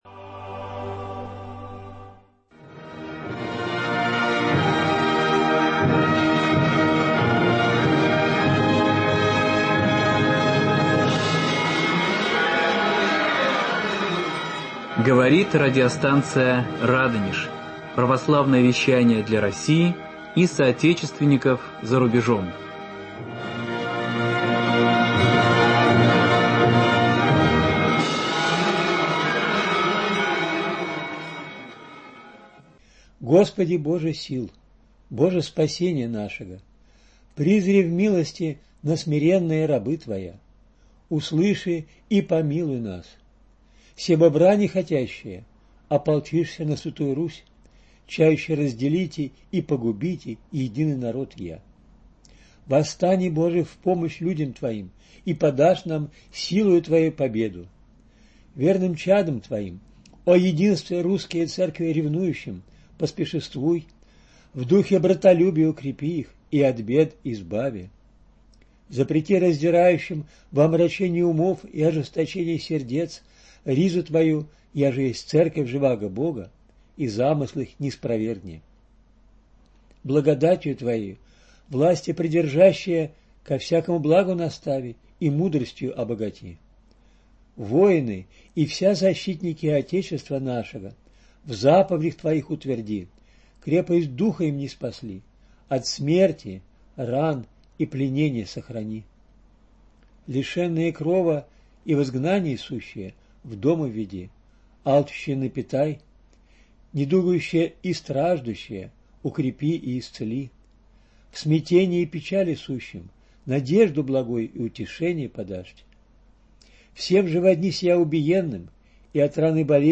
В студии радио "Радонеж"